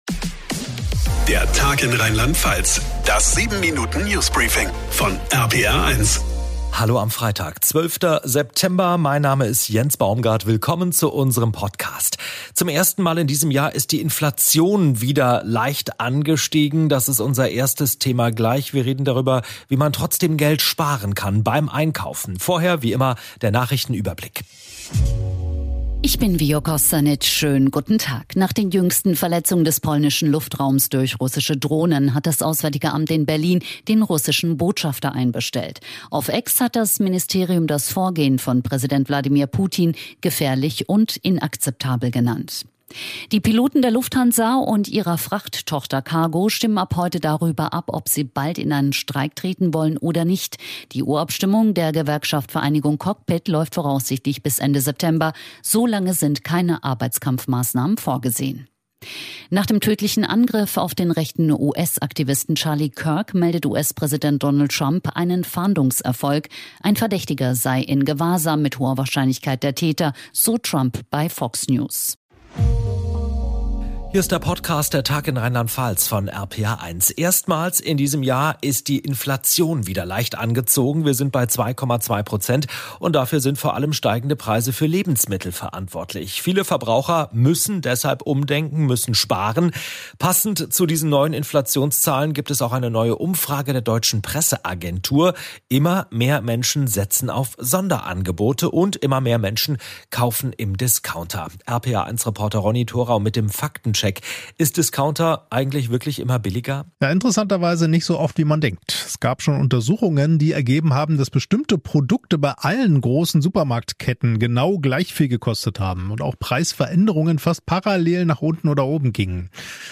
Genres: Daily News, News, Society & Culture